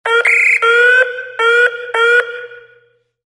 Звуки рупора
Еще сирена, другая (просят обратить внимание)